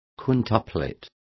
Complete with pronunciation of the translation of quintuplets.